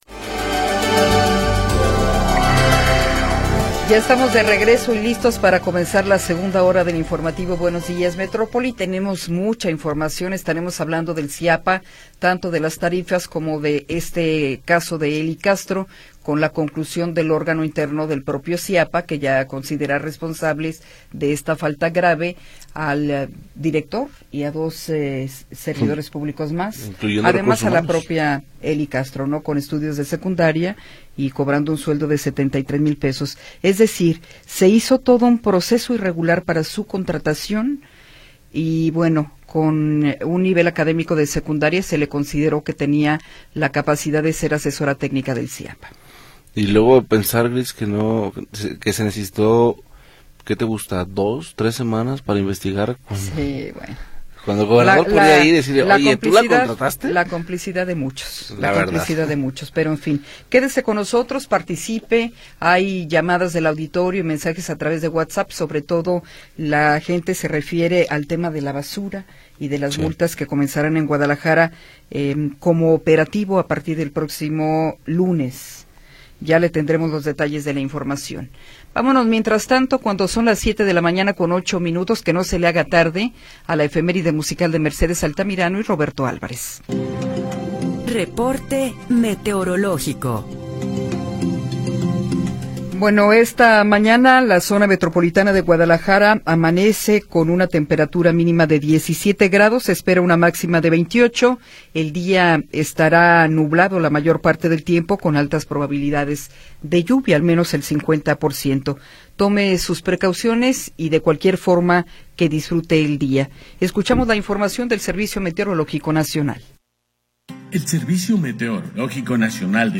Segunda hora del programa transmitido el 1 de Agosto de 2025.